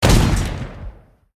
academic_cannon_shot_a.ogg